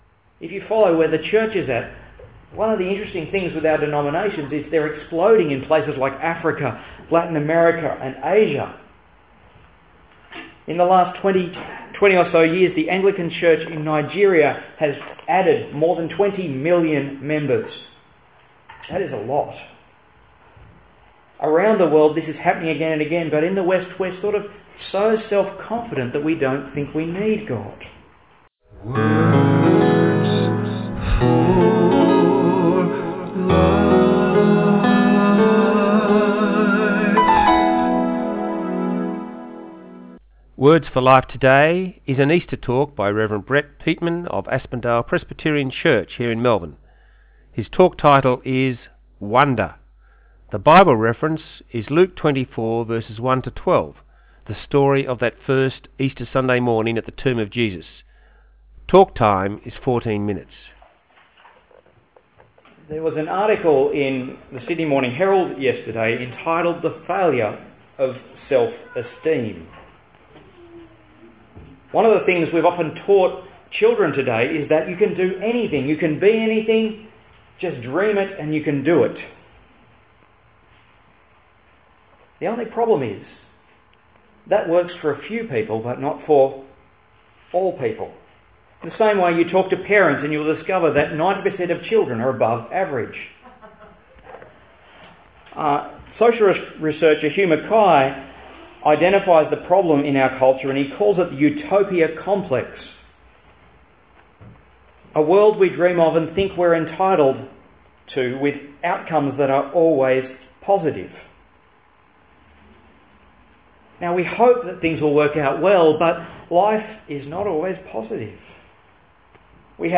His talk is about the first Easter morning and is entitled "Wonder?".